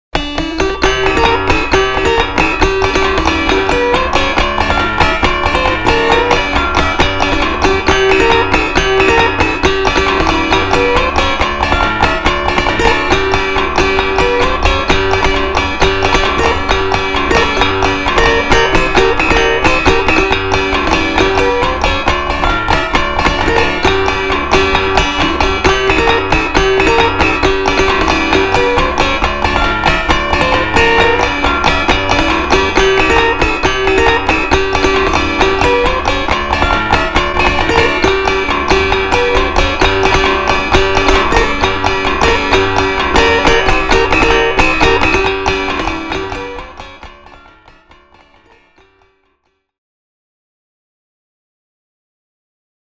dolceola
First up, here's one played on the dolceola. This piece was recorded years ago as a piano solo.